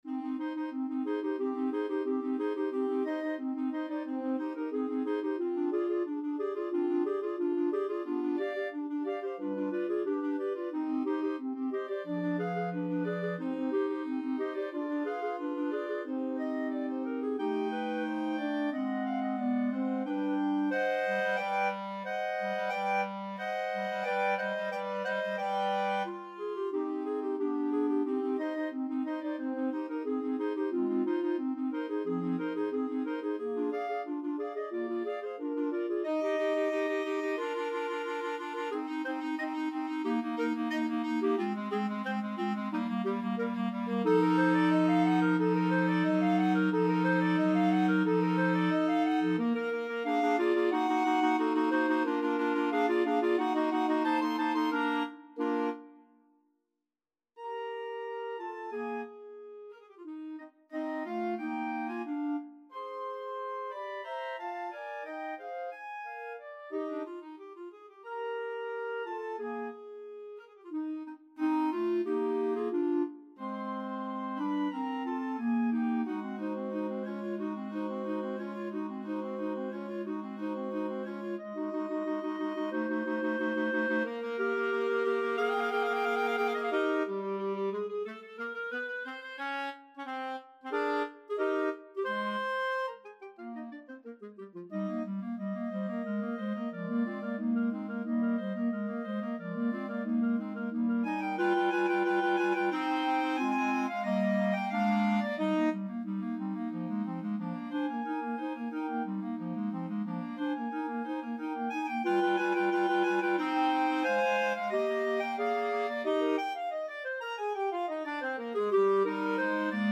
Allegro Molto =c.180 (View more music marked Allegro)
4/4 (View more 4/4 Music)
Classical (View more Classical Clarinet Quartet Music)